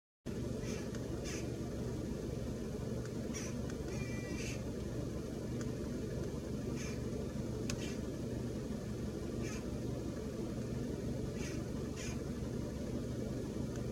鳴 き 声：キョキョ、キュキュ、キョンキョン等とやや高い声で鳴く。
鳴き声１